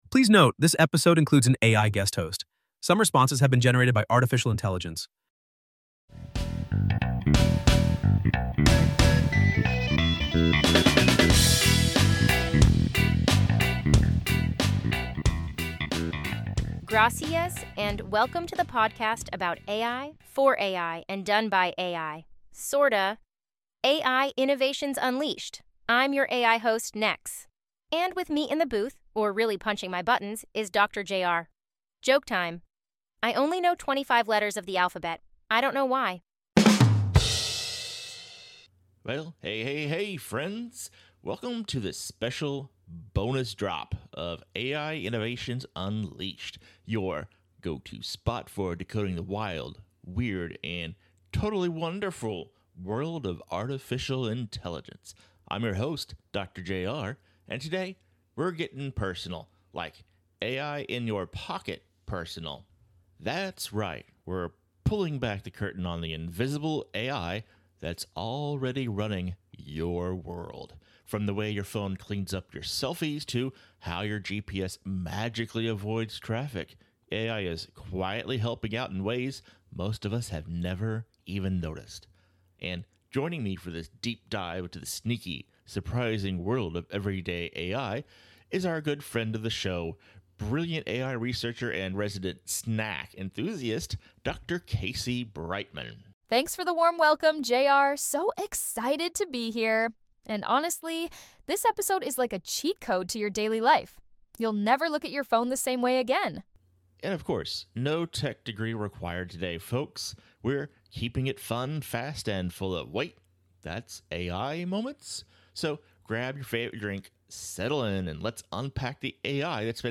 We play a few games, share a few laughs, and ask some big questions—like when AI is helpful, and when it’s just plain creepy.